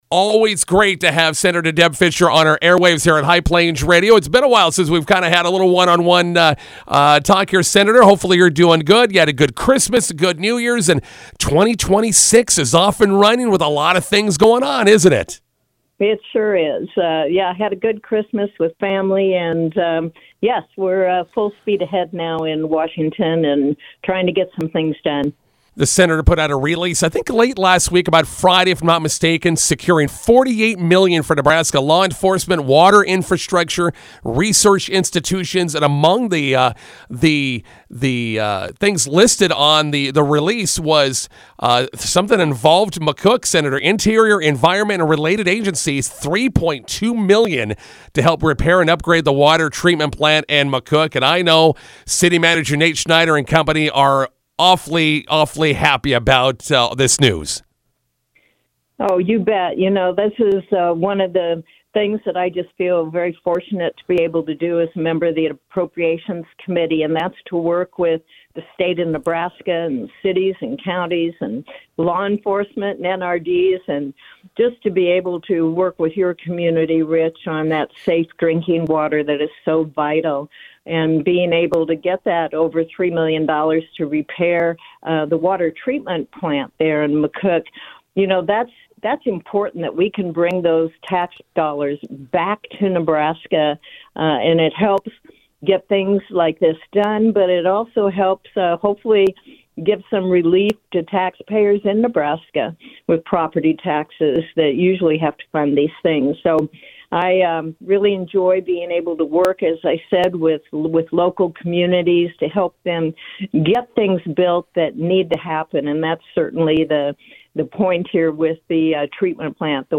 INTERVIEW: Sen. Deb Fischer helping secure $48 Million for Nebraska Law Enforcement, Water Infrastructure, Research Institutions